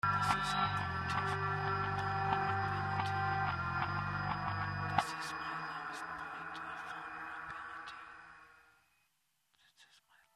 clip of whispers between track three and four
reversed, with bass guitar lowered